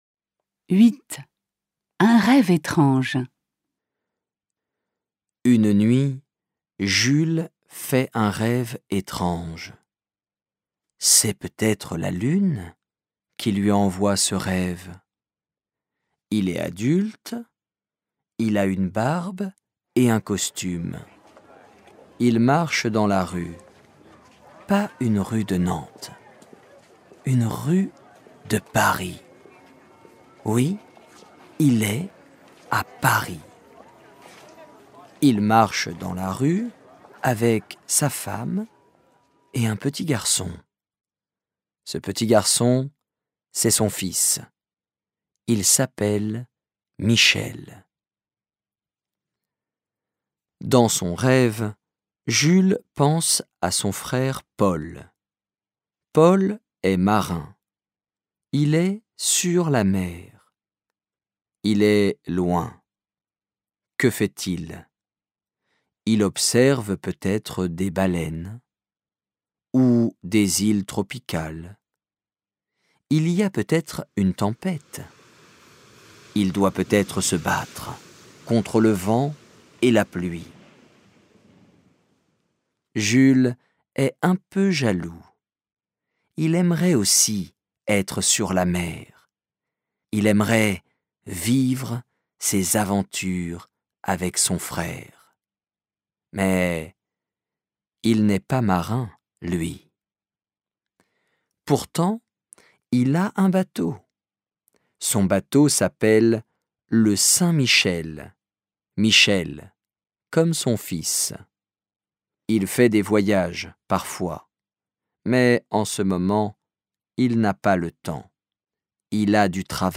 Lecture simplifiée: Les romans de Jules Verne